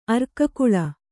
♪ arkakuḷa